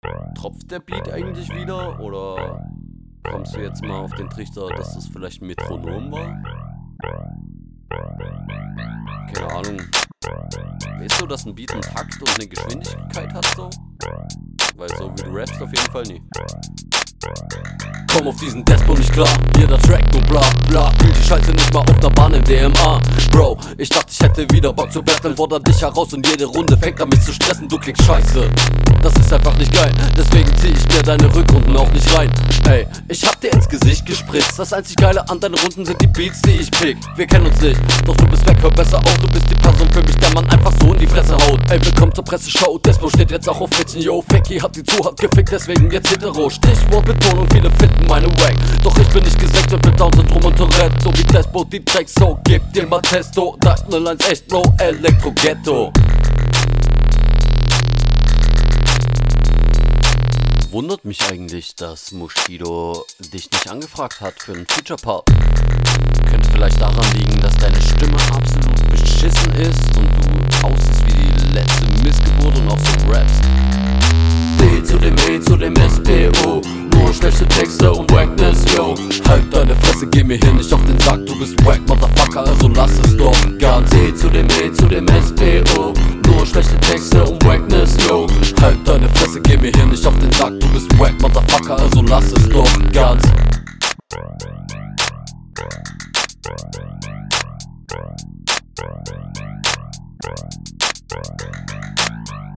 Lustiges Intro :D Stimmlich fehlt mir dann doch etwas das Leben, grade auf son mächtigen, …